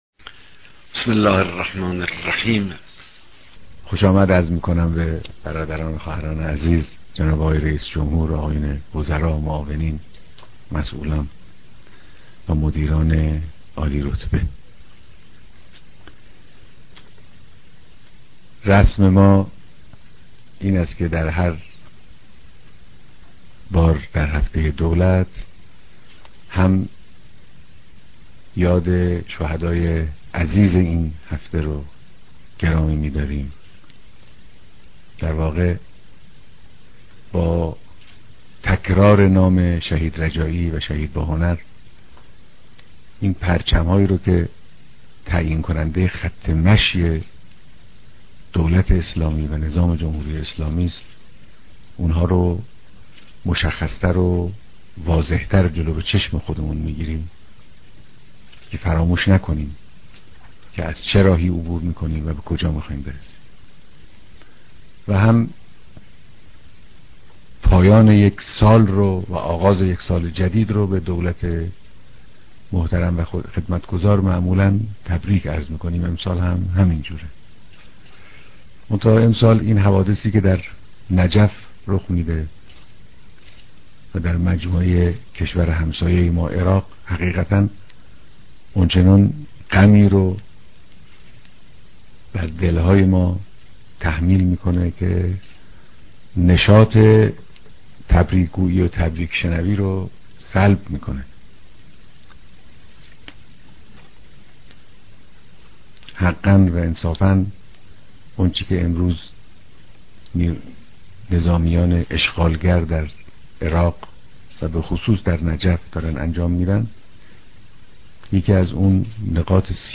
بيانات ديدار با رئيس جمهور و اعضاى هيأت دولت